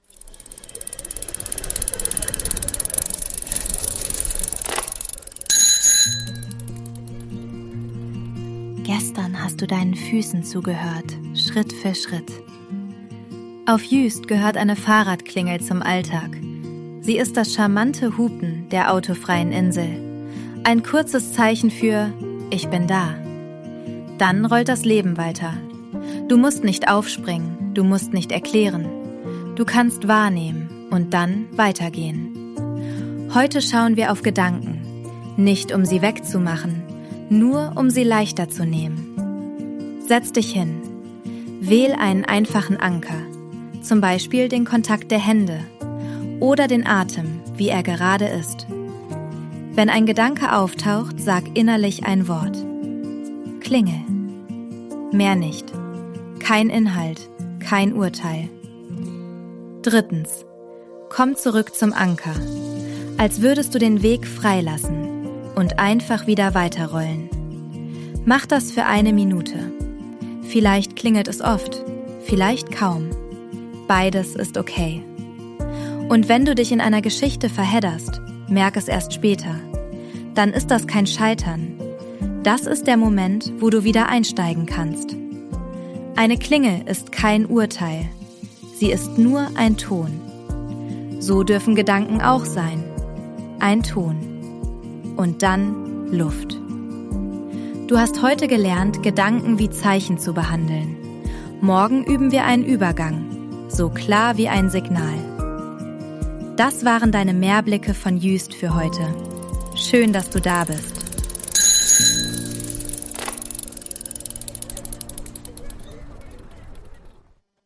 Kurverwaltung Juist Sounds & Mix: ElevenLabs und eigene Atmos